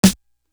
Air It Out Snare.wav